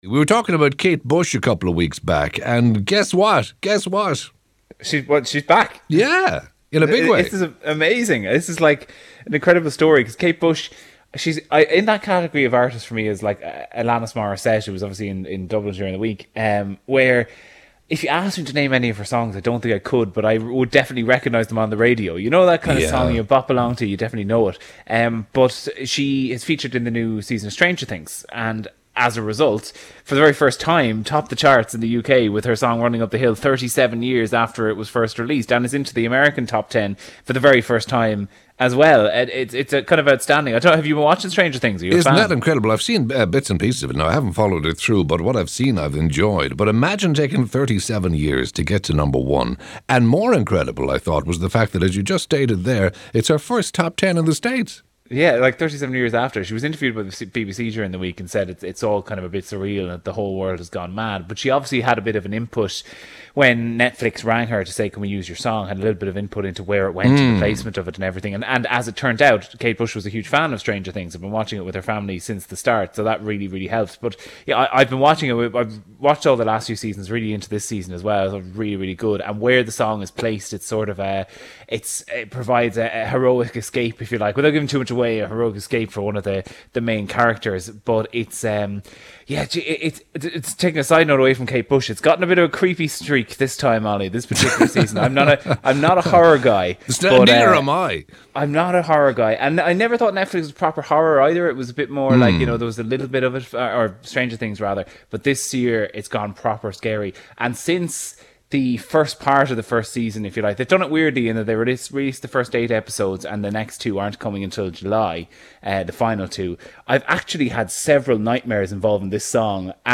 Always an interesting conversation